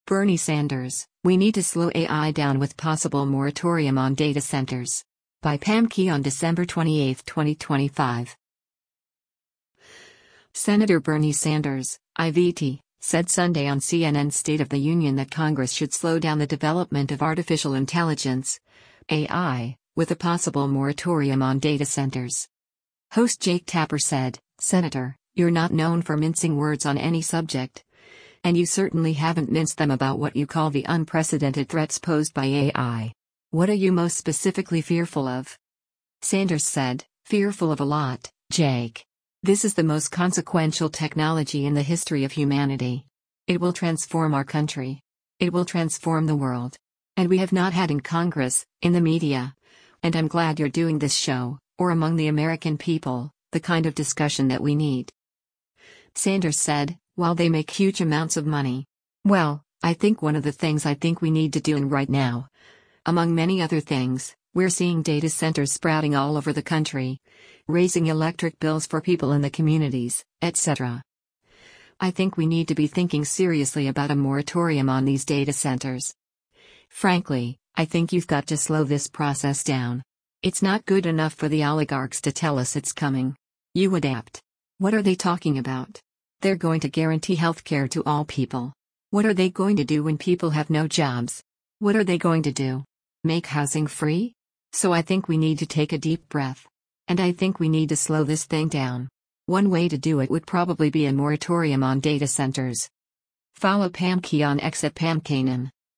Senator Bernie Sanders (I-VT) said Sunday on CNN’s “State of the Union” that Congress should slow down the development of artificial intelligence (AI) with a possible moratorium on data centers.